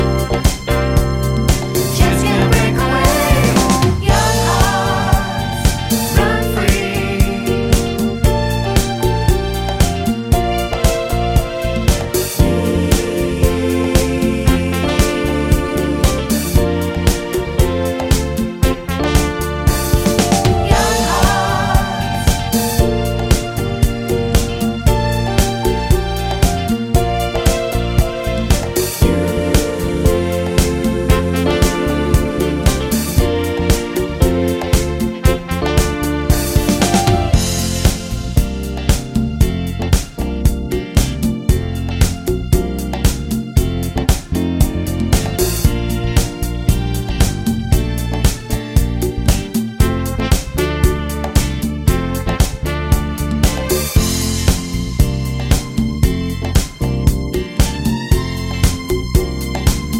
No Saxes Disco 4:09 Buy £1.50